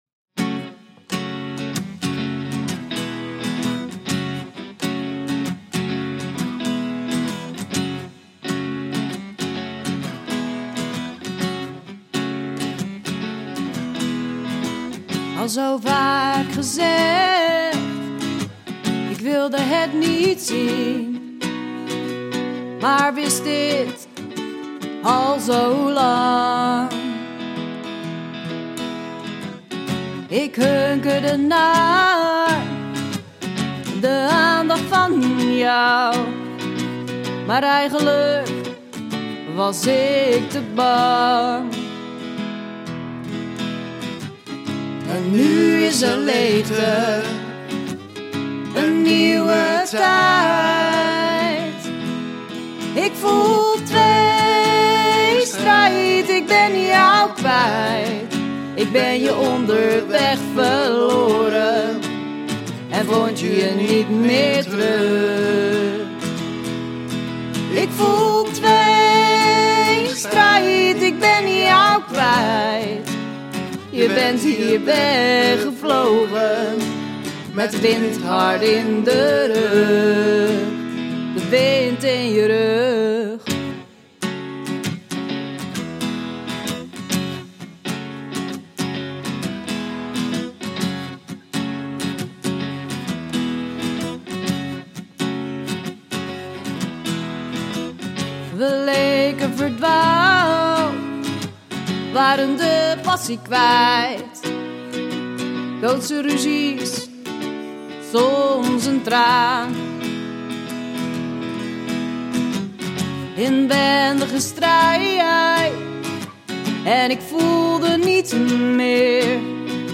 Puur, twee gitaren en twee zangstemmen.